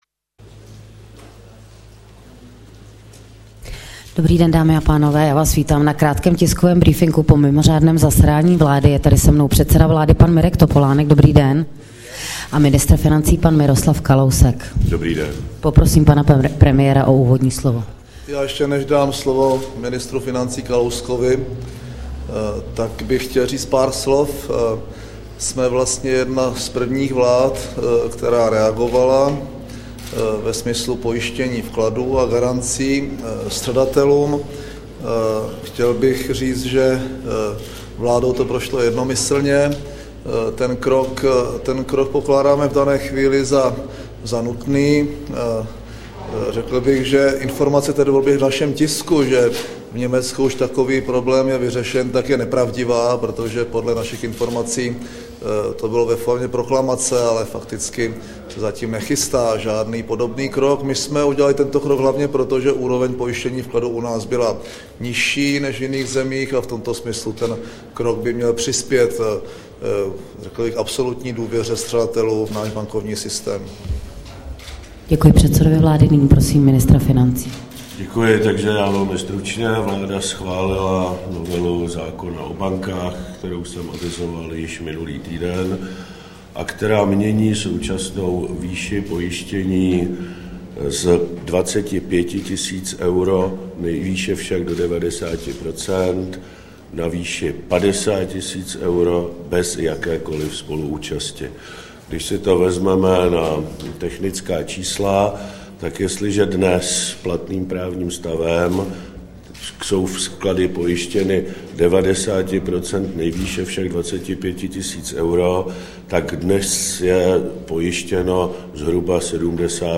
Tisková konference po mimořádném jednání vlády o pojištění vkladů drobných střadatelů 14. října 2008